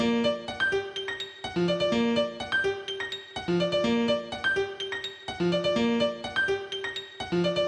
悲哀的Lo Fi钢琴
标签： 125 bpm Chill Out Loops Piano Loops 1.29 MB wav Key : Unknown
声道立体声